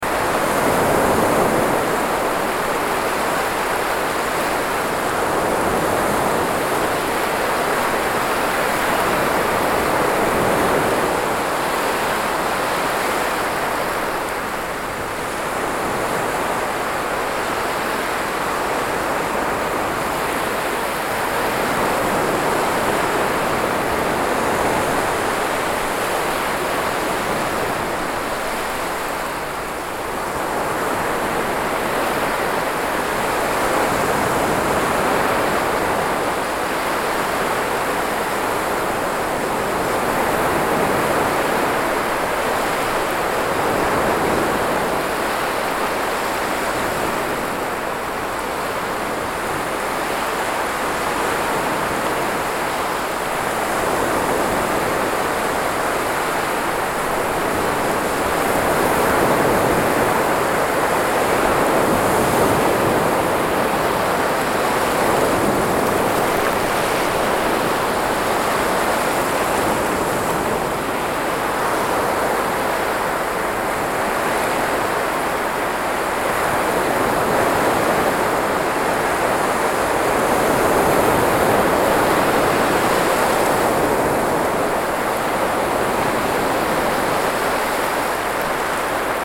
3d Sounds 24 Jul, 2025 3D Ocean Waves Sound Effect – Seamless Loop Read more & Download...
3D-ocean-waves-sound-effect-seamless-loop.mp3